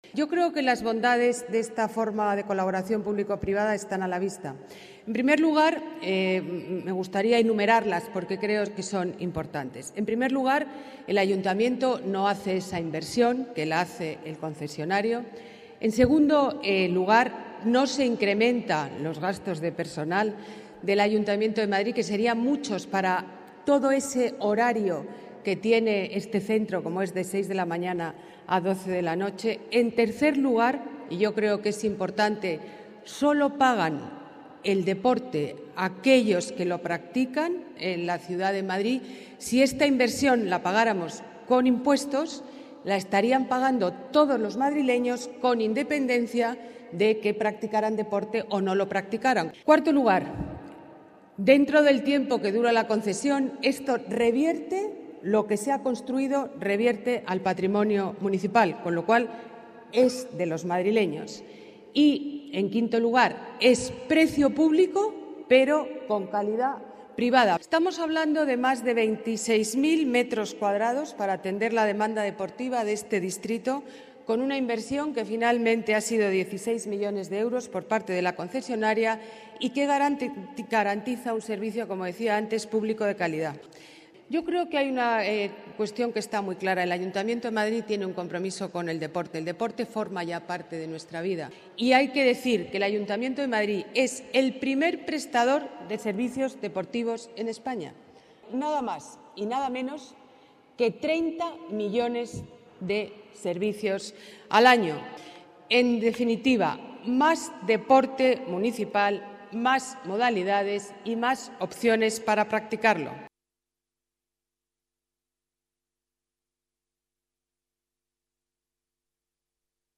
Nueva ventana:Declaraciones de la alcaldesa, Ana Botella: Inauguración Centro Deportivo Vallehermoso